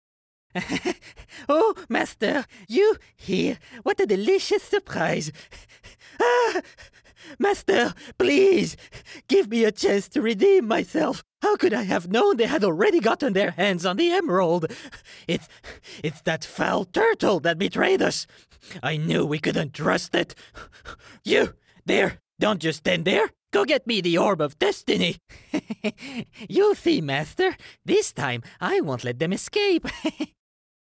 落ち着いた／穏やか
知的／クール
収録　　宅録、
Voice Actor Sample6（悪役手下）[↓DOWNLOAD]